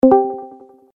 user-joined.mp3